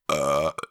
burp sound
Category 🗣 Voices
burp funny human mouth voice sound effect free sound royalty free Voices